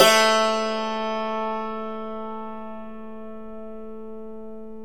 Index of /90_sSampleCDs/E-MU Formula 4000 Series Vol. 4 – Earth Tones/Default Folder/Hammer Dulcimer
DLCMR A1-R.wav